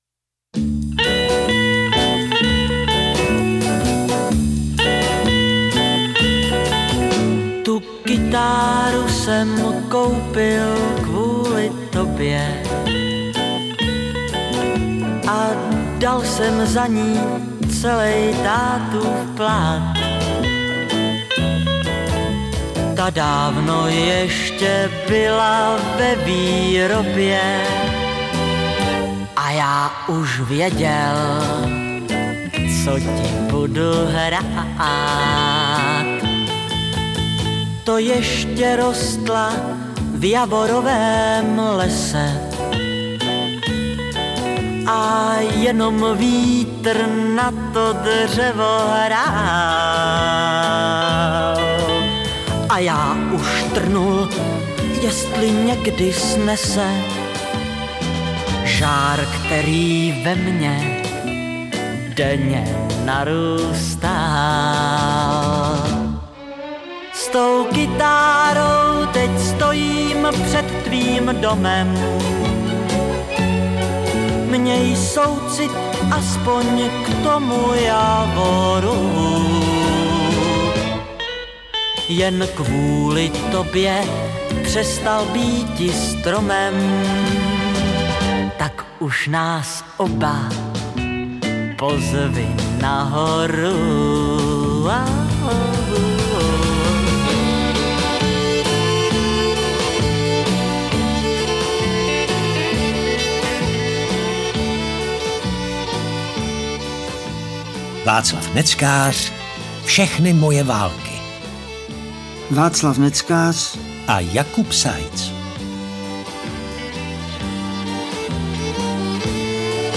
• AudioKniha ke stažení Václav Neckář - Všechny moje války
zpěvák Václav Neckář promlouvá svými písněmi.